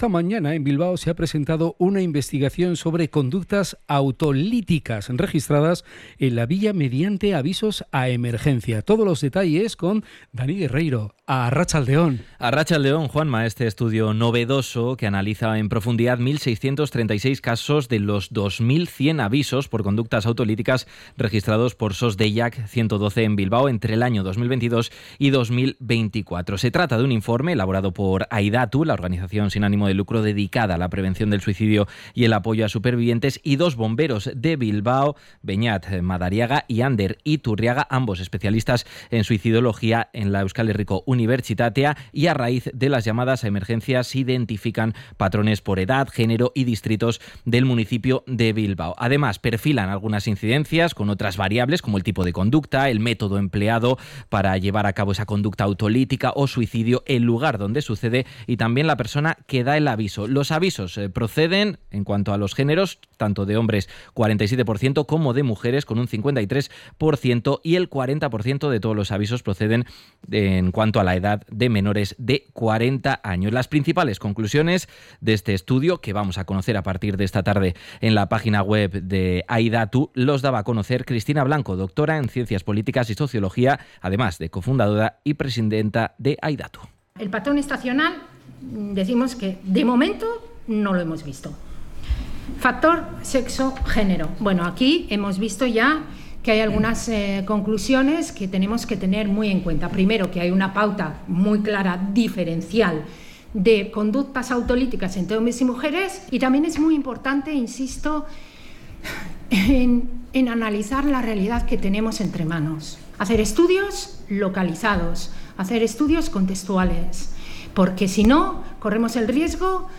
Presentación del estudio de Aidatu / RADIO POPULAR-HERRI IRRATIA